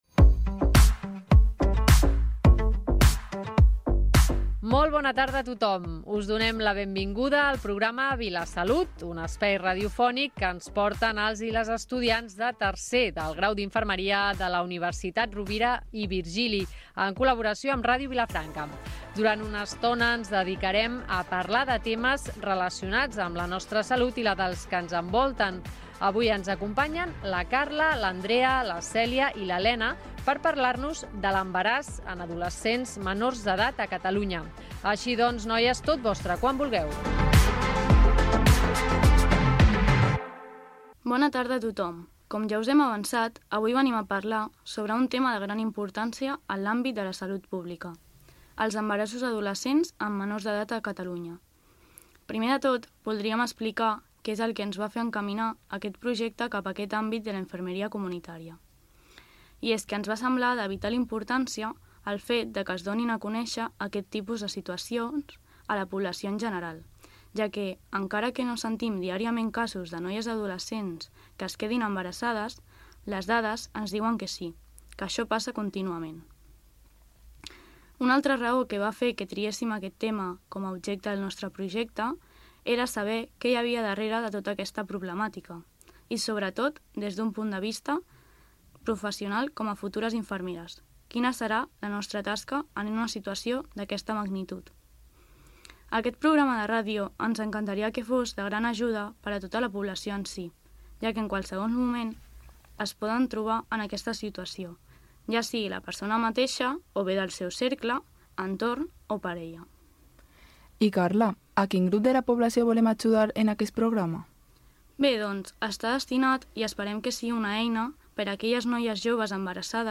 Divulgació
Programa fet pels estudiants de la Facultat d’Infermeria de la Universitat Rovira Virgili.